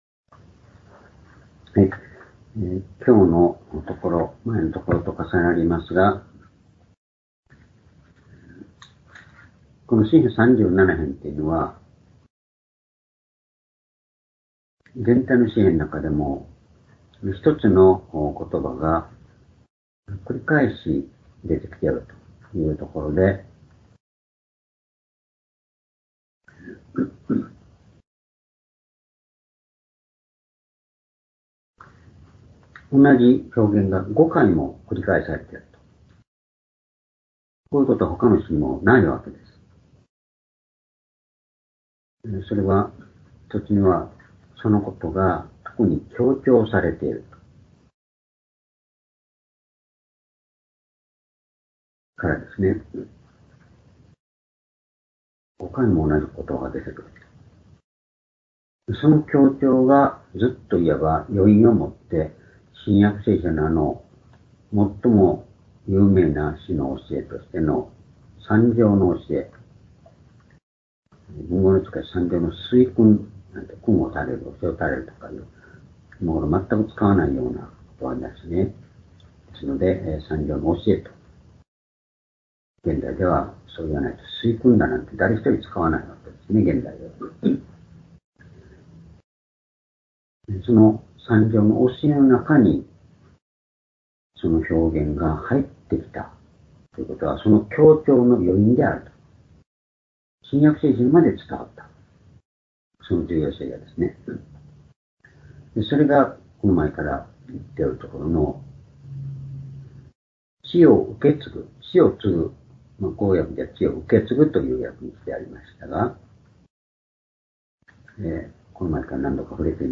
（主日・夕拝）礼拝日時 2023年１０月３日（夕拝） 聖書講話箇所 「地をを受け継ぐ者の歩み」 詩編37編22～29 ※視聴できない場合は をクリックしてください。